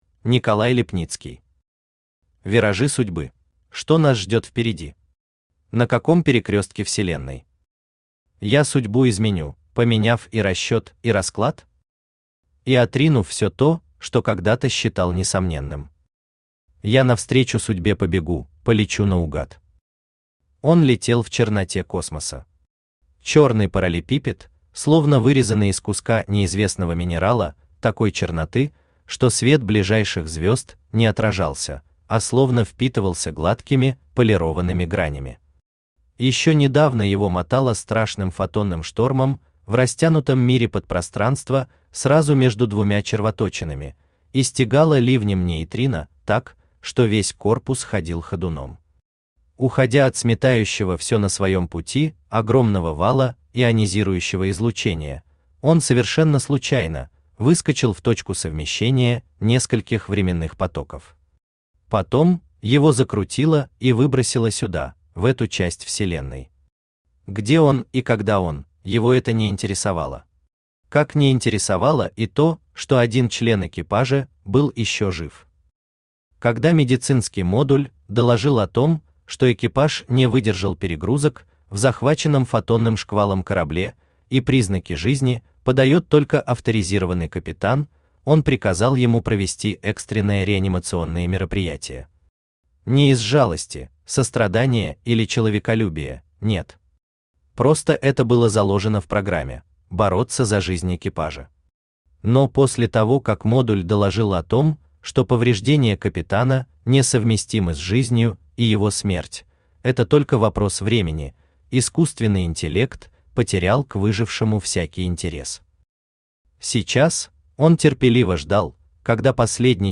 Аудиокнига Виражи судьбы | Библиотека аудиокниг
Aудиокнига Виражи судьбы Автор Николай Иванович Липницкий Читает аудиокнигу Авточтец ЛитРес.